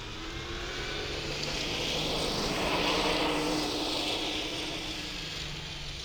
Subjective Noise Event Audio File (WAV)
utah_electric_2007.wav